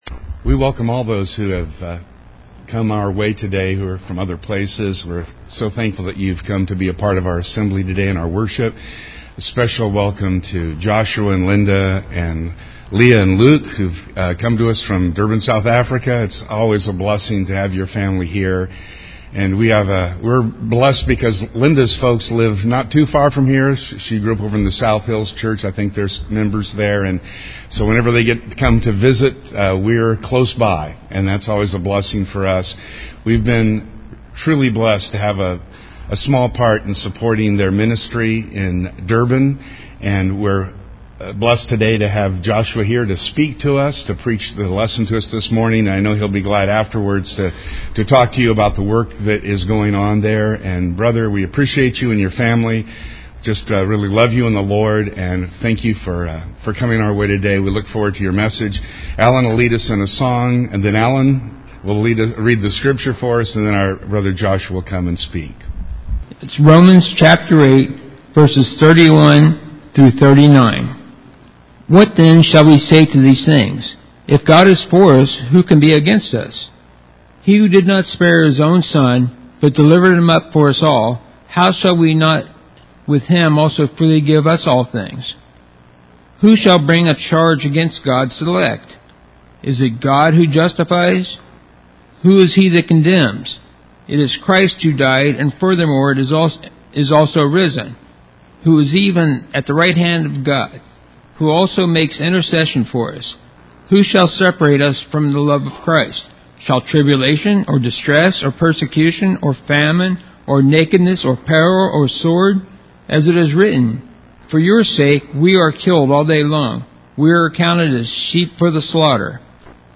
gives the lesson as our guest speaker!